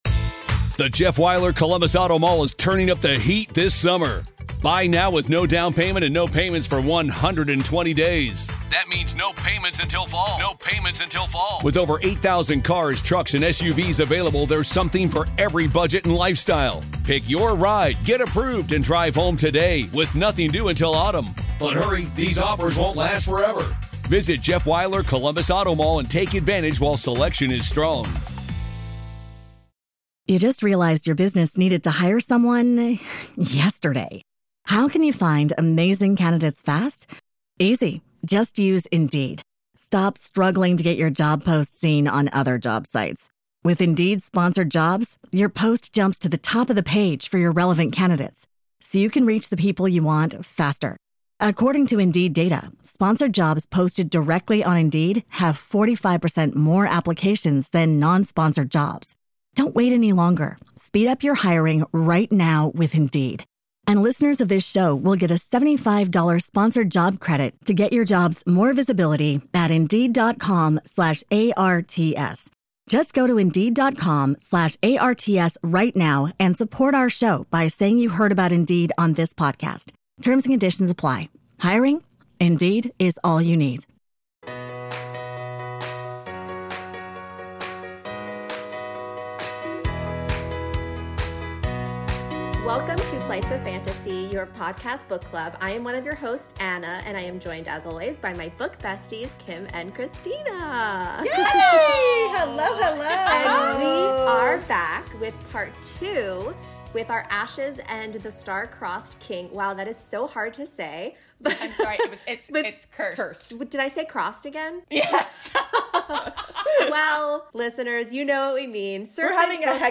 Enjoy the second part of our episode on The Ashes and the Star Cursed King by Carissa Broadbent. Get ready for ALL the quote readings, gushing about our beloved Raihn, lots of laughs and yes, of course, some tears.